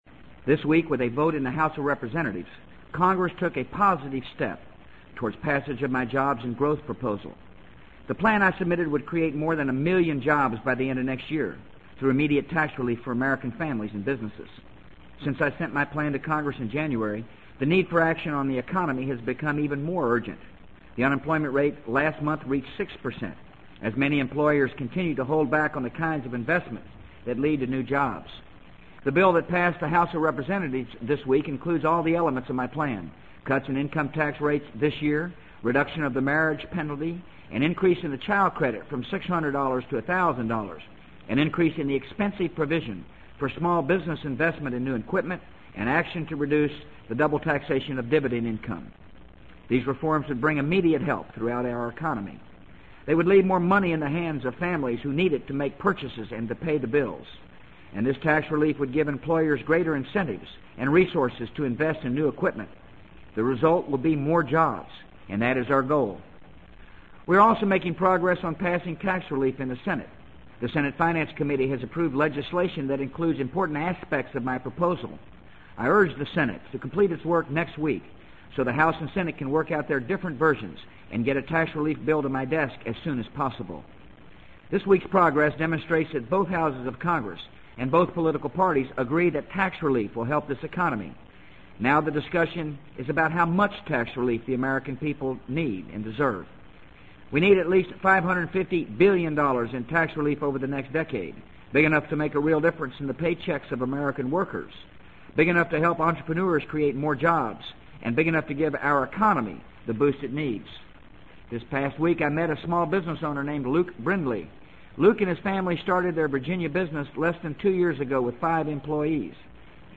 【美国总统George W. Bush电台演讲】2003-05-10 听力文件下载—在线英语听力室